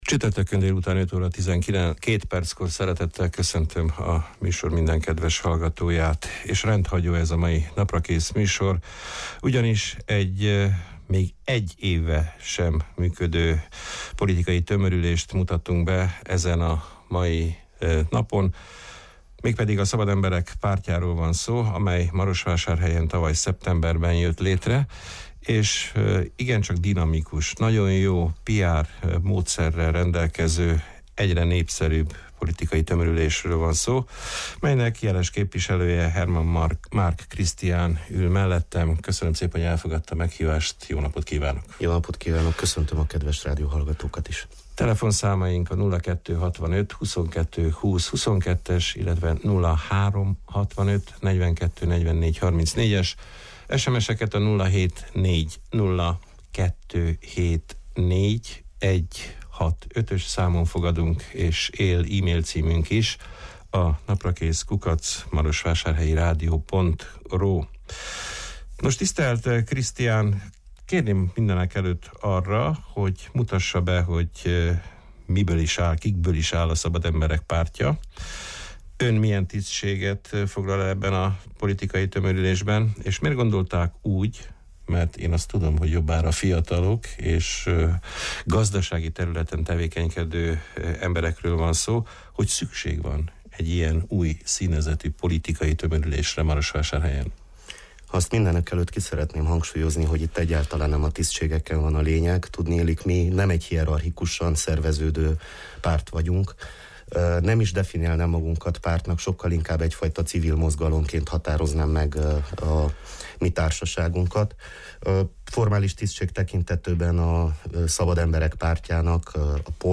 Tavaly szeptemberben új párt jelent meg a marosvásárhelyi politikai palettán, amely máris polgármesterjelölttel és teljes, fele-fele arányban román és magyar jelölteket tartalmazó, tanácsosi listával áll a választók elé. A Szabad Emberek Pártja (POL) célkitűzéseiről, összetételéről, távlati terveiről beszélgettünk a tegnapi Naprakész műsorban